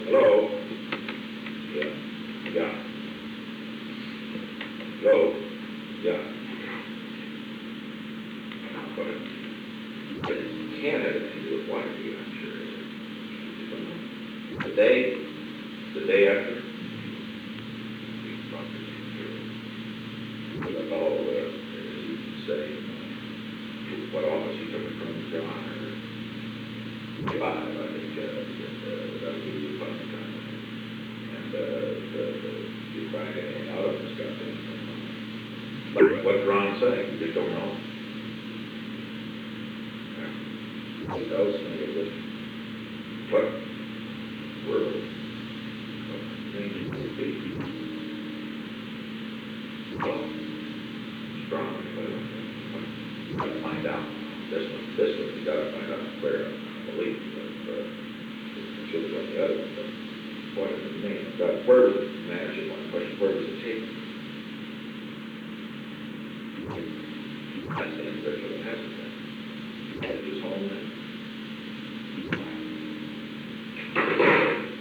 Secret White House Tapes
Location: Executive Office Building
The President talked with John D. Ehrlichman.